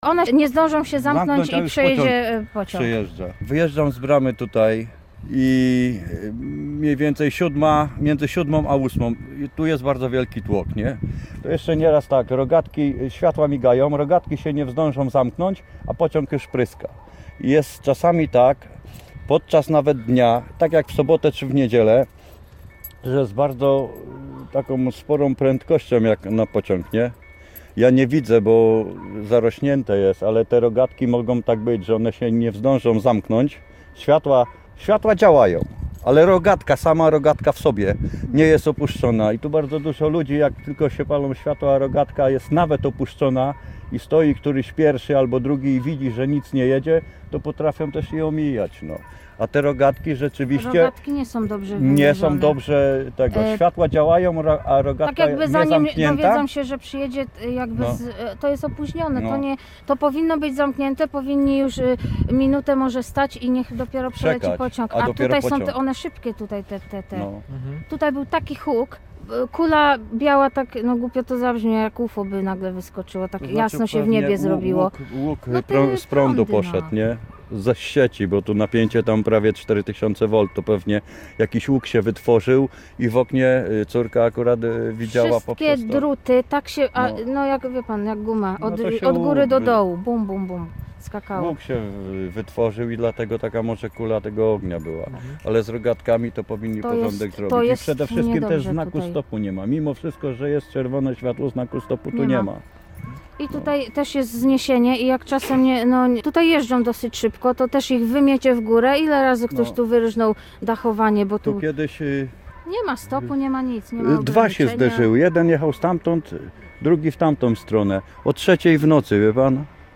Mieszkający w pobliżu tego przejazdu kolejowego łodzianie narzekają na funkcjonowanie rogatek: Posłuchaj: Nazwa Plik Autor Wypadek na przejeździe kolejowym na Śląskiej audio (m4a) audio (oga) Warto przeczytać Pogoda na piątek.